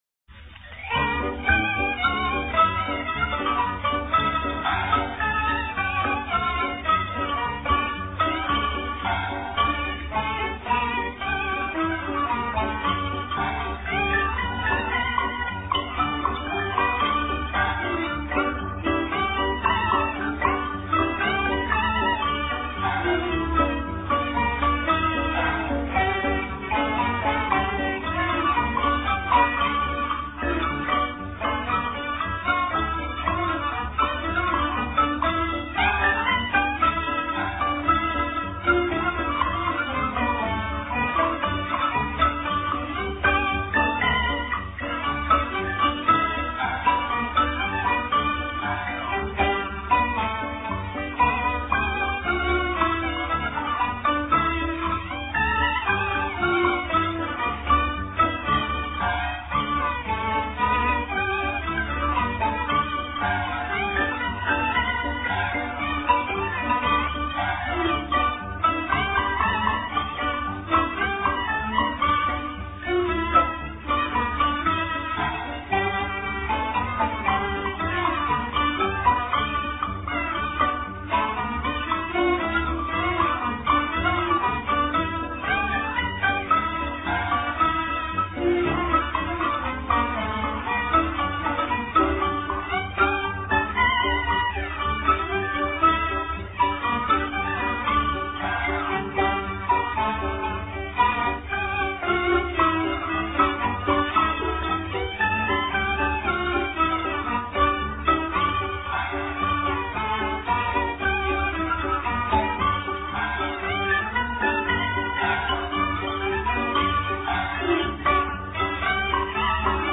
可謂粵味濃郁、立意鮮明、旋律優美、可以朗朗上口。
此曲的開始和結尾主旋律完全相同，最後一句漸漸收慢，是廣東音樂傳統習慣。